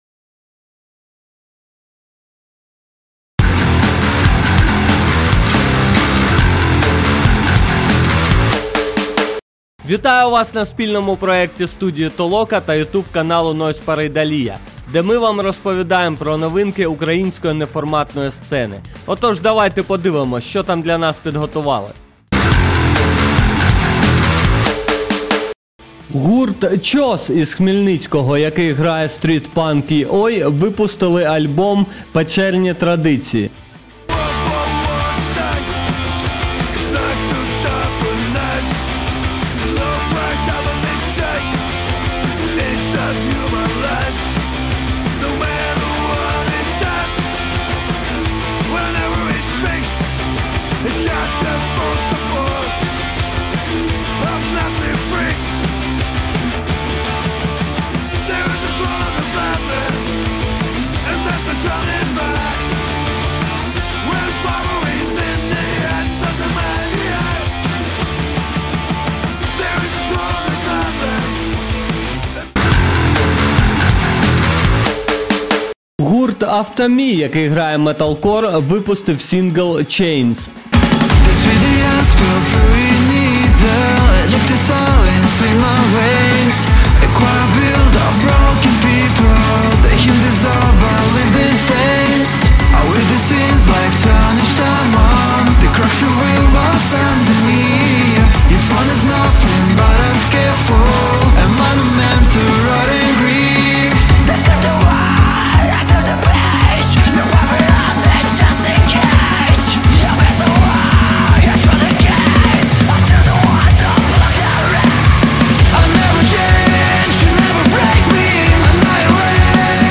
Стиль: Подкаст